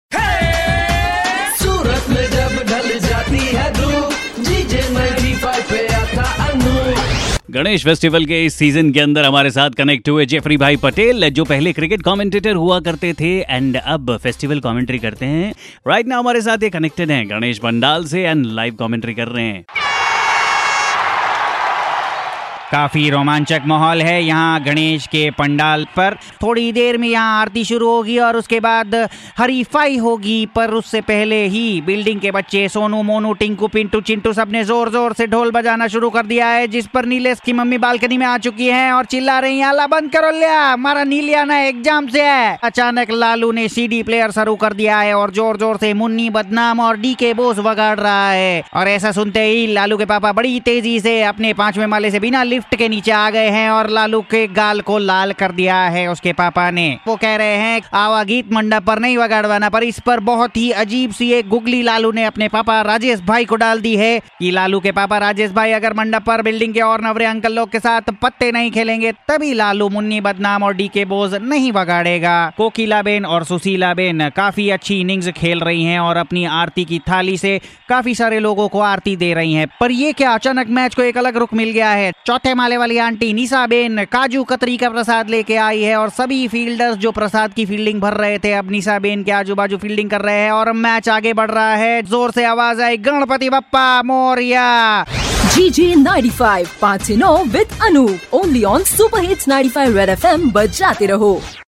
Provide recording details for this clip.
GANESH PANDAL COMMENTARY